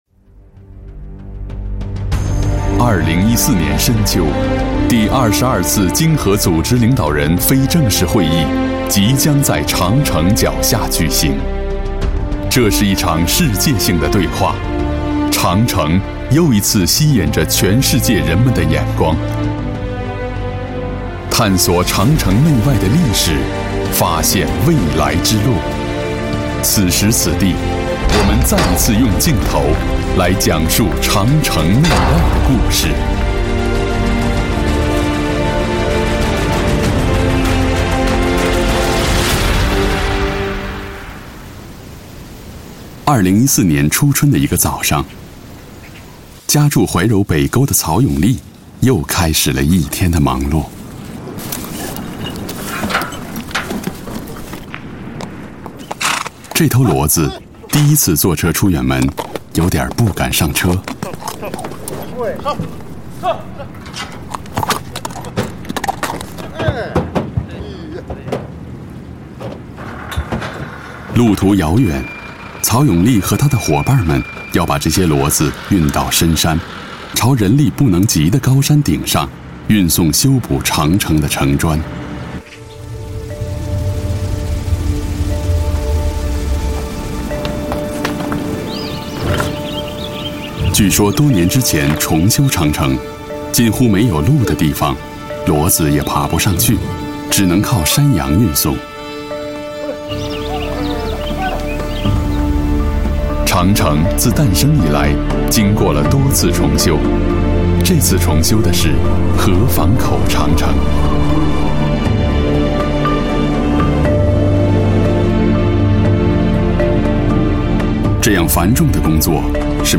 • 男11 国语 男声 纪录片 cctv高清纪录片 长城内外 历史 恢宏 大气浑厚磁性|沉稳|娓娓道来